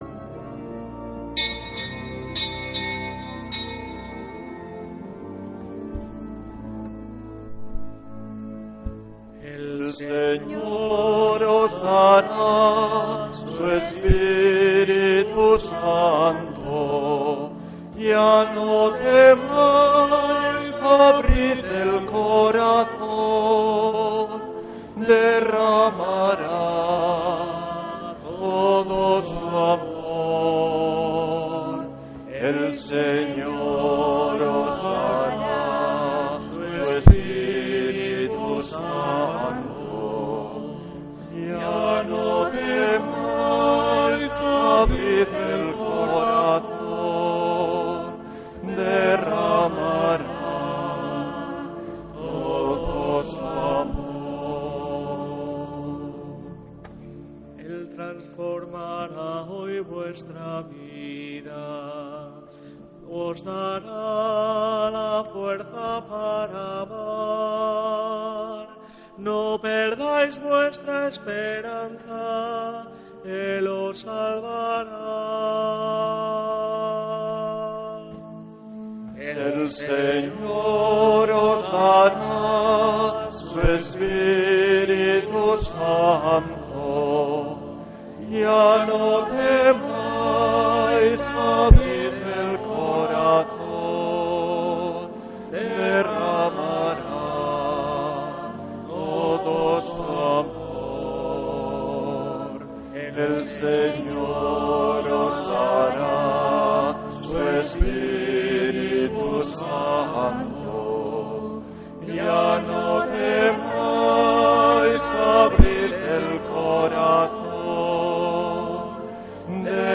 Santa Misa desde San Felicísimo en Deusto, domingo 18 de enero de 2026